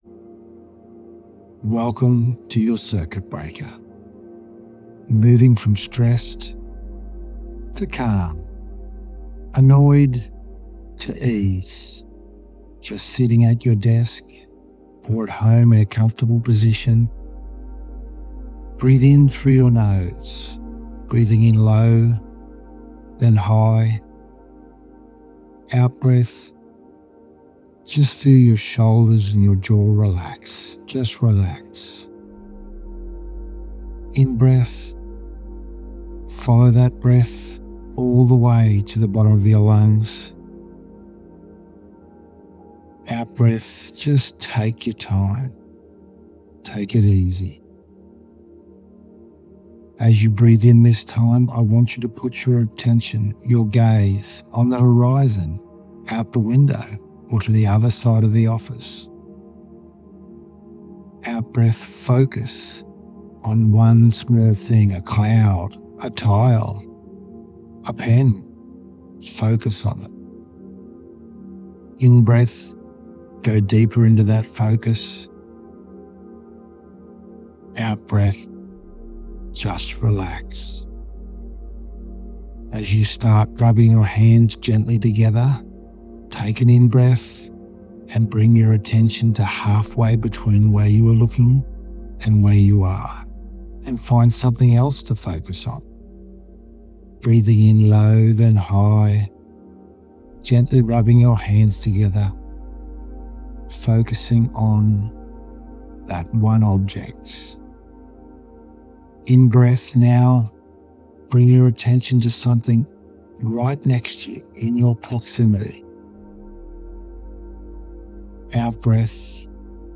Backed by music that supports brainwave regulation, it’s a powerful tool for anyone needing a moment of stillness in a busy day.